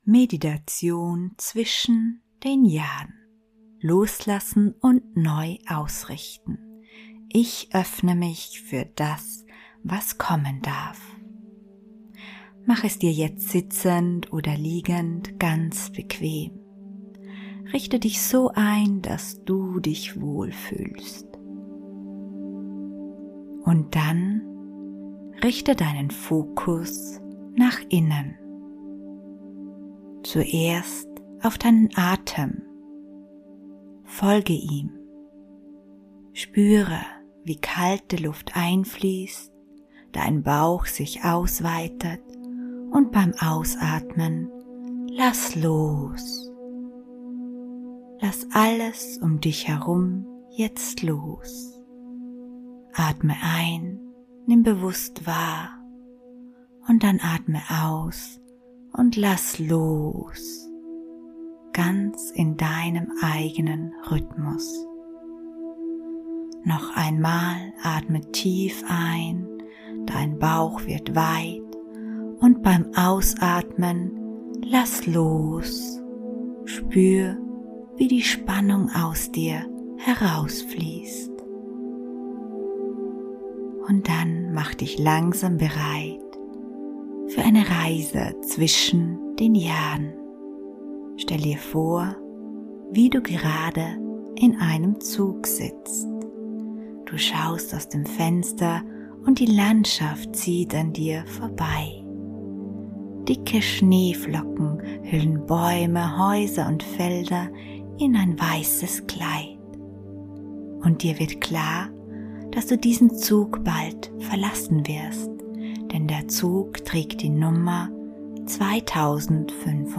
Beschreibung vor 1 Tag Diese geführte Meditation zwischen den Jahren unterstützt Frauen 35+ beim bewussten Jahresabschluss und inneren Loslassen. Im Fokus stehen Loslassen alter Glaubenssätze, emotionale Entlastung und mentale Neuorientierung zum Jahreswechsel. Die Jahreswechsel-Meditation verbindet Reflexion, Selbstliebe und innere Klarheit.